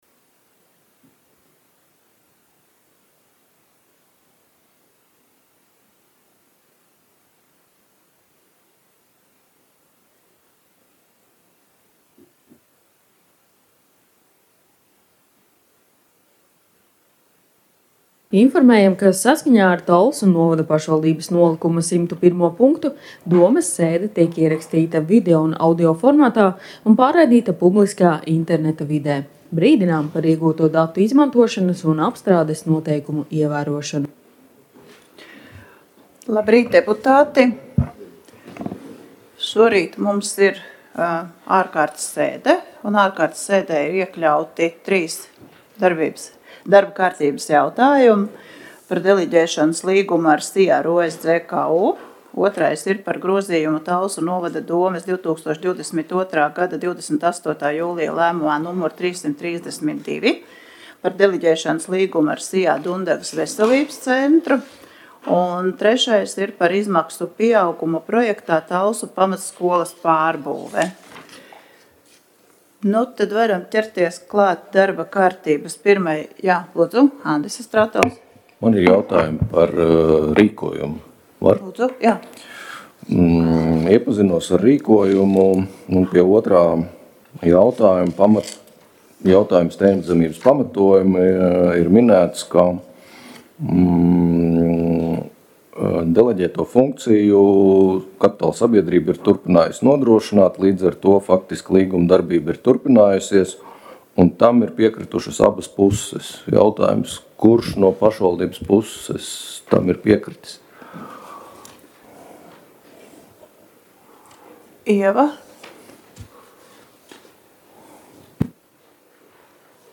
Talsu novada domes ārkārtas sēde Nr. 2